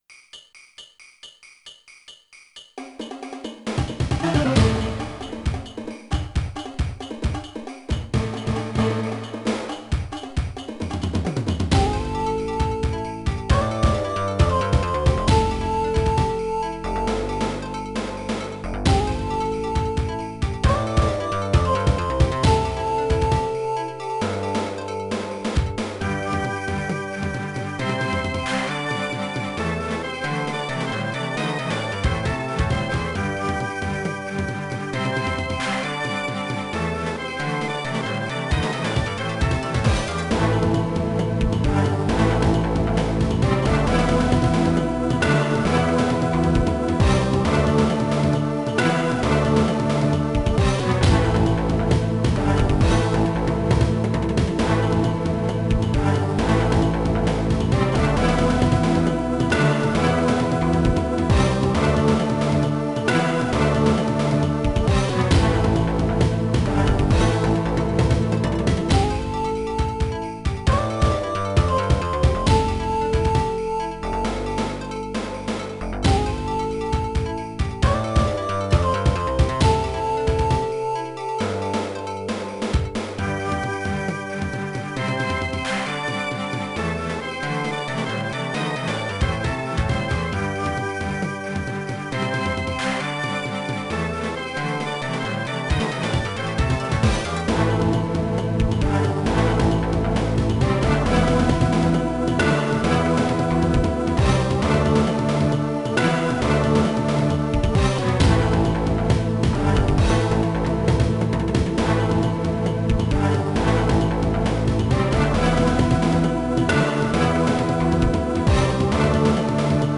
Stage one theme